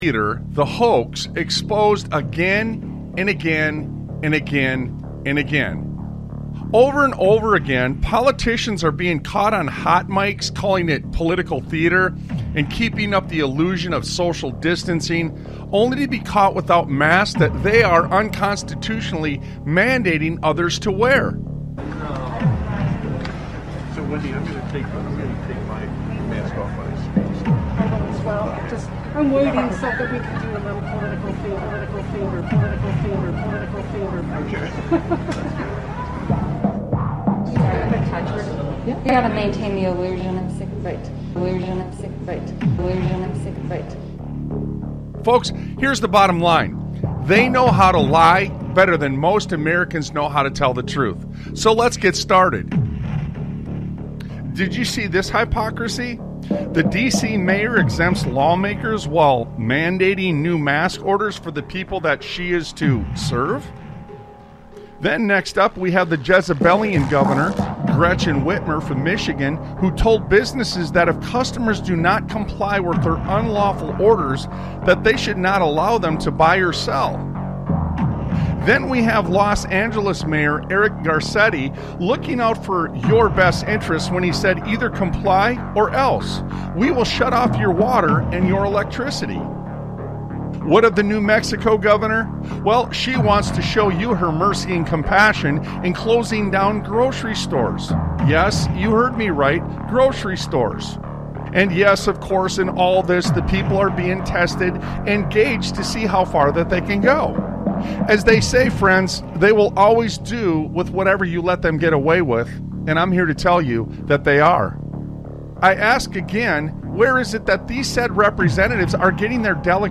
Talk Show Episode, Audio Podcast, Sons of Liberty Radio and A STORY You ALL Need To Hear on , show guests , about A STORY You ALL Need To Hear, categorized as Education,History,Military,News,Politics & Government,Religion,Christianity,Society and Culture,Theory & Conspiracy